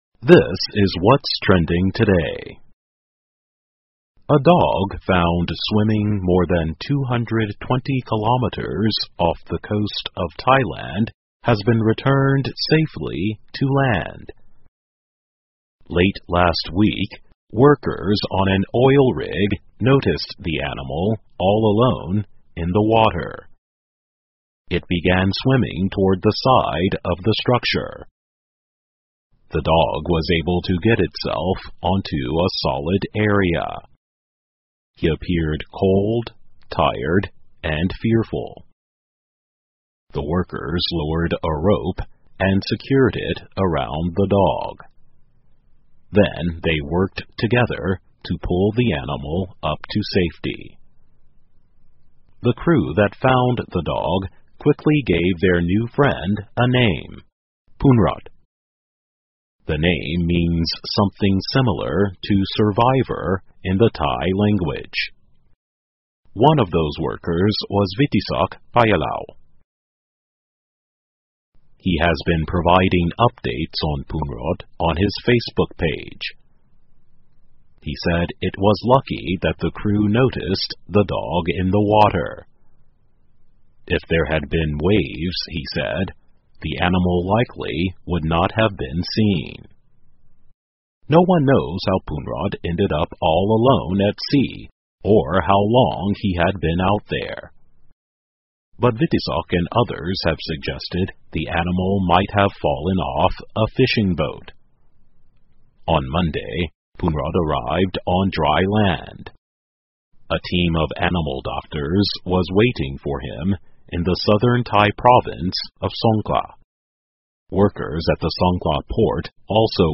VOA慢速英语2019 泰国小狗"海漂"220公里后获救 听力文件下载—在线英语听力室